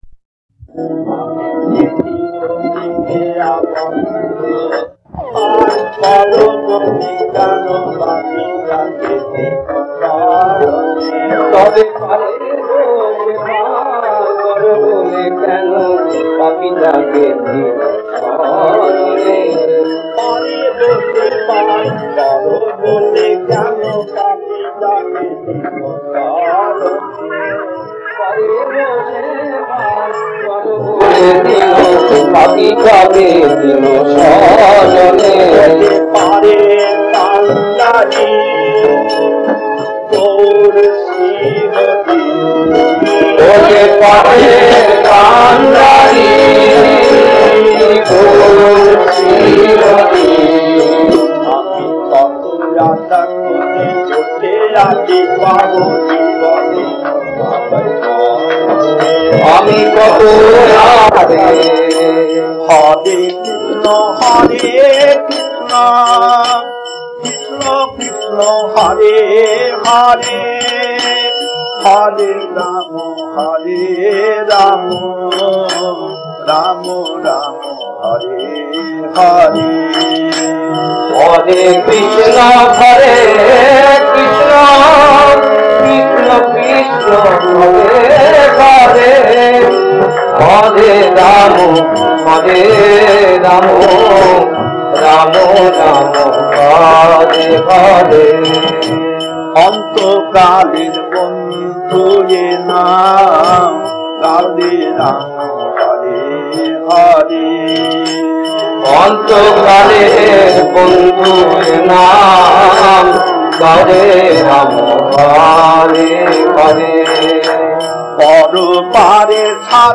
Kirtan